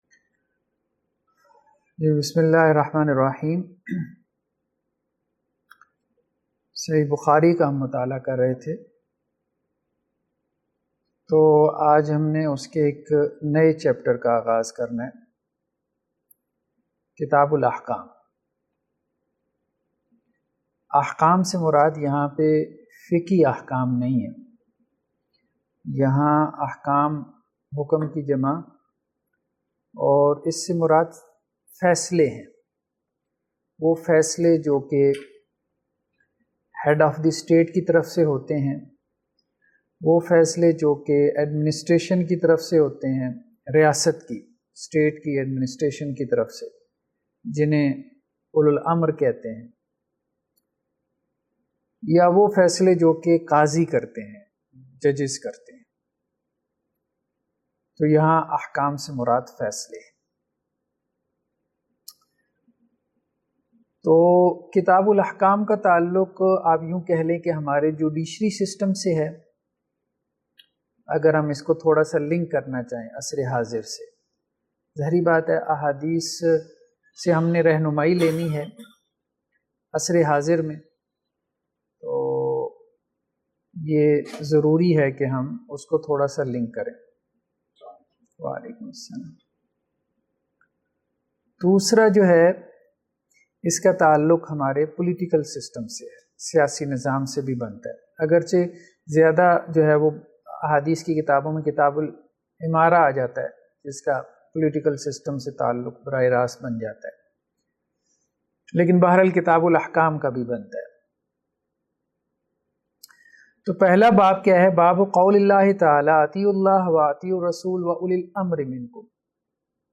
EP -10 II Hadith Class II Sahih Bukhari II Kitab Al-Ahkam.mp3